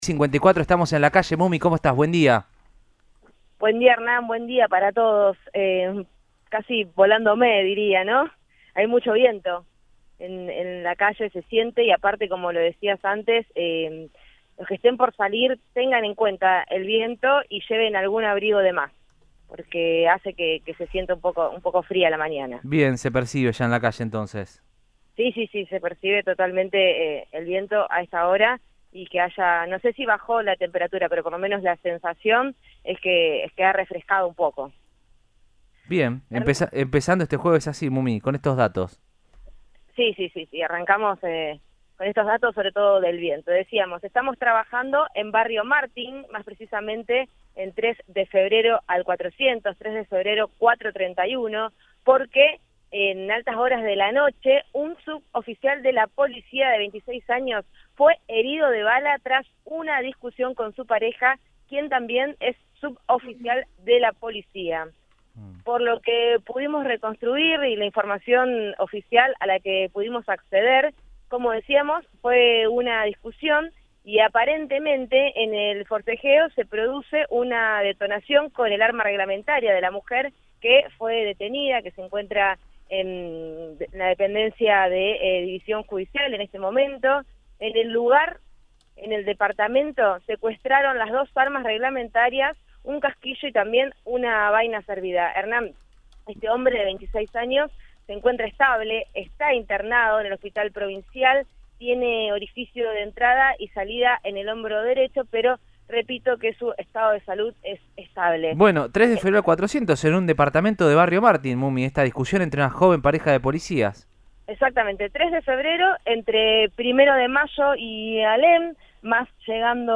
Audio. Aymo reveló algunos resultados del primer día de los radares en Circunvalación
Osvaldo Aymo, subsecretario de la Agencia Provincial de Seguridad Vial (APSV), detalló al móvil de Cadena 3 Rosario, en Radioinforme 3, que “entre las 11 y las 13 hubo 5 excesos de velocidad”.